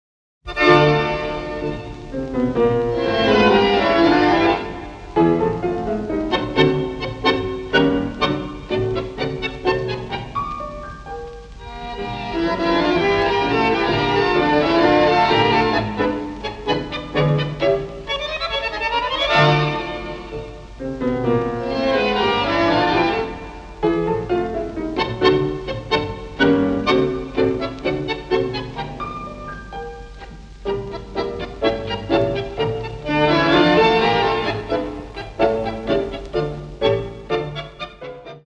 Klassische Tangos (wo ist die Grenze?)